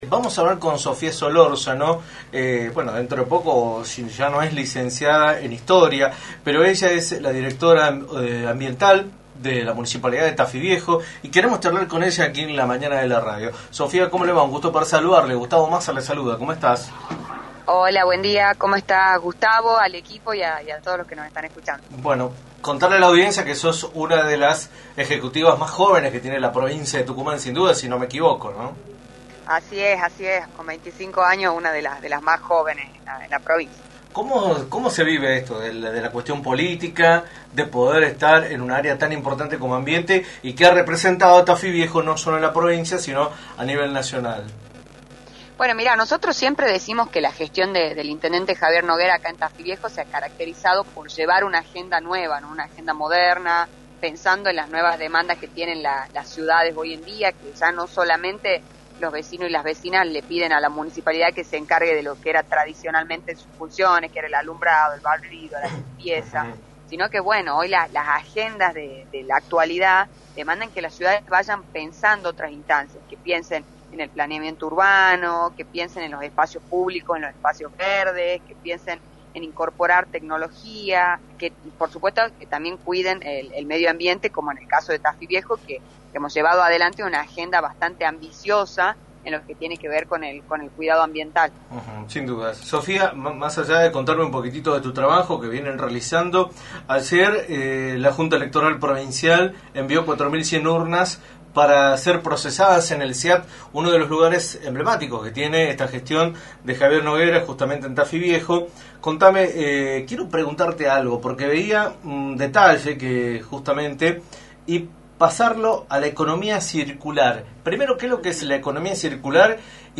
en entrevista para “La Mañana del Plata”, por la 93.9.